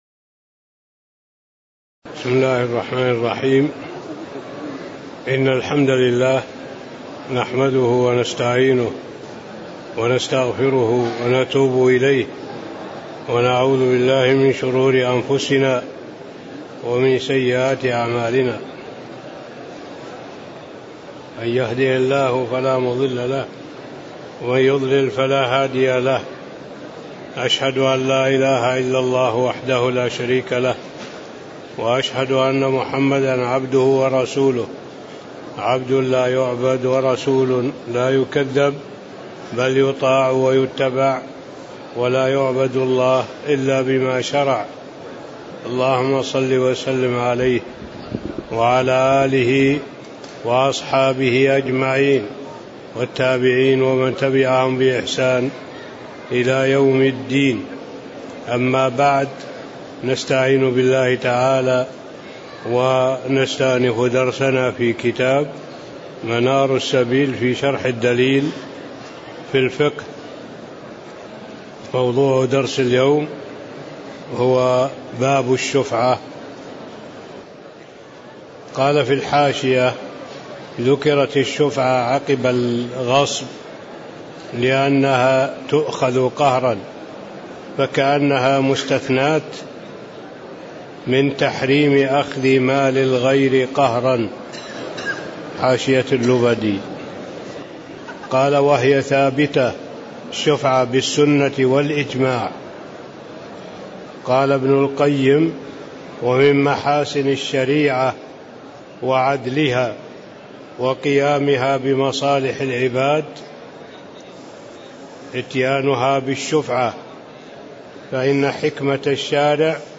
تاريخ النشر ١٥ ربيع الأول ١٤٣٧ هـ المكان: المسجد النبوي الشيخ